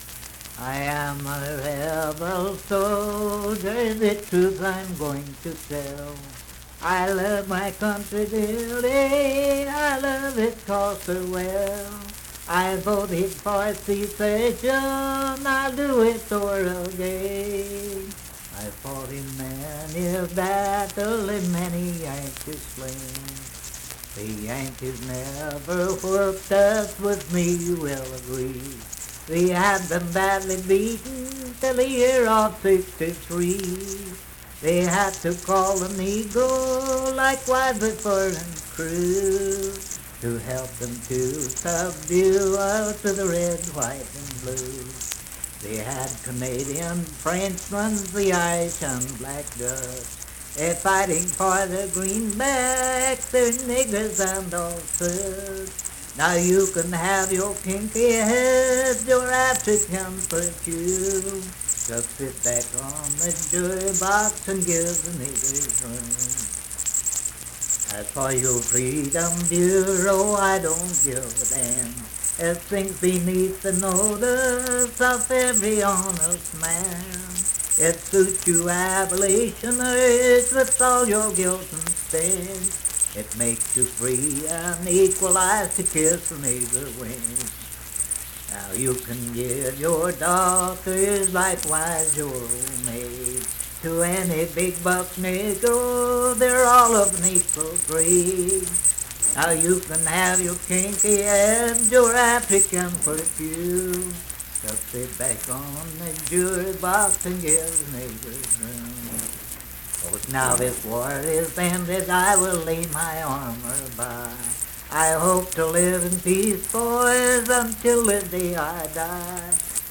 Unaccompanied vocal music
Voice (sung)
Huntington (W. Va.), Cabell County (W. Va.)